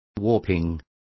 Complete with pronunciation of the translation of warpings.